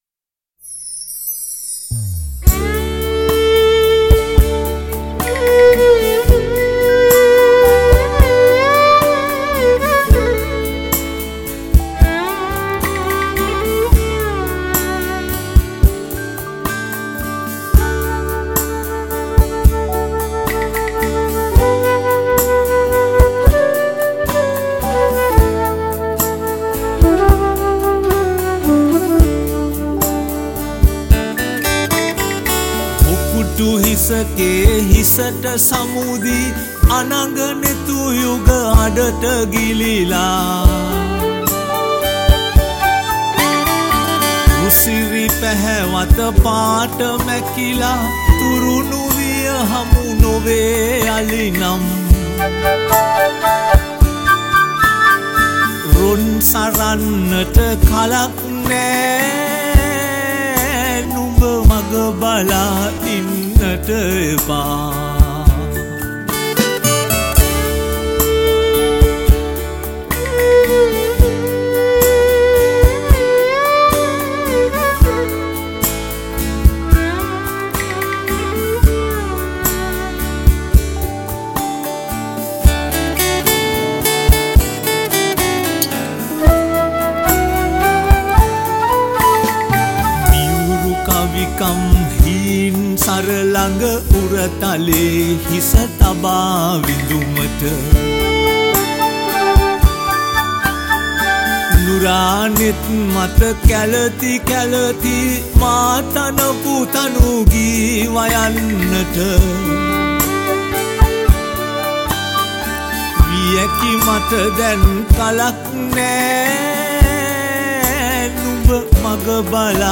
All these songs were recorded (or remastered) in Australia.
Vocals